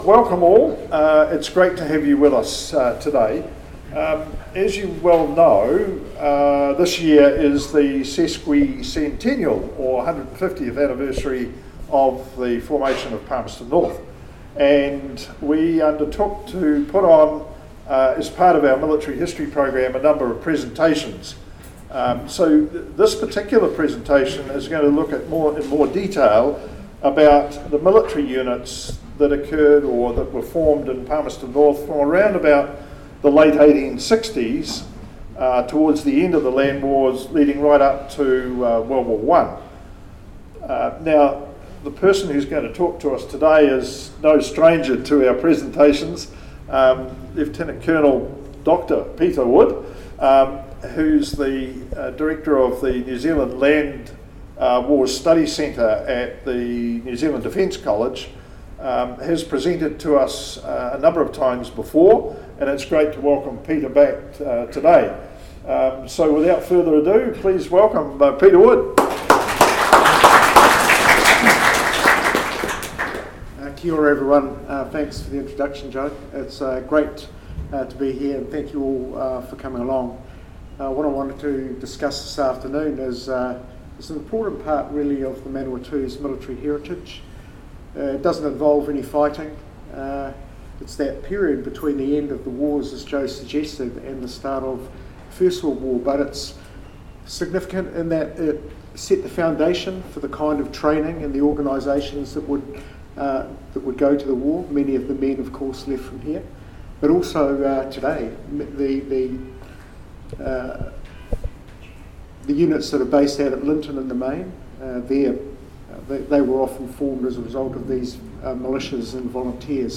Presentation
Trentham Military Camp at Palmerston North City Library on 11 March 2021.